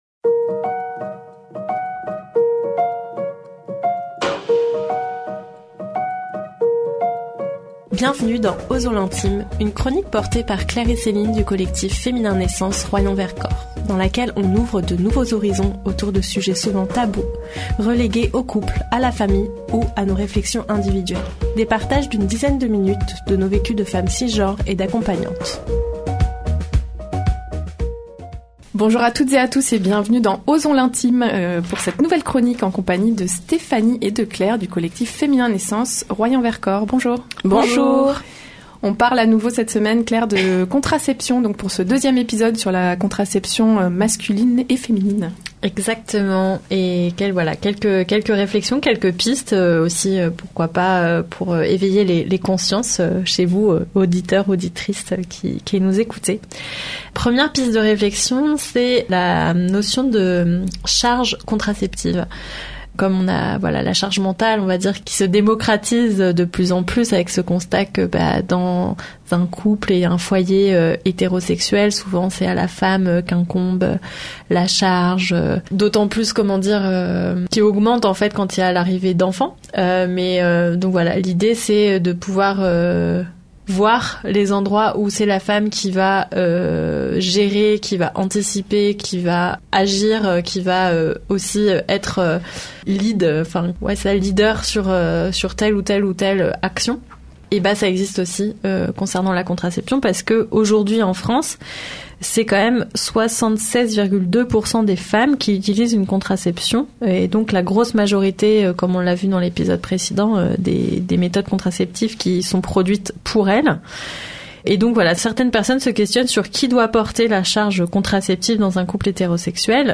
Des partages d’une dizaine de minutes autour de leurs vécus de femmes cisgenres et d’accompagnantes.